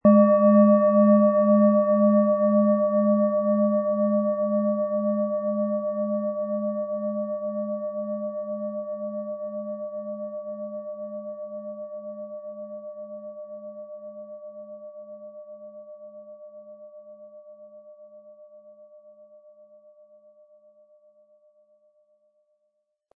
Planetenton 1
Handgearbeitete tibetische Schale mit dem Planetenton Neptun.
Um den Originalton der Schale anzuhören, gehen Sie bitte zu unserer Klangaufnahme unter dem Produktbild.
MaterialBronze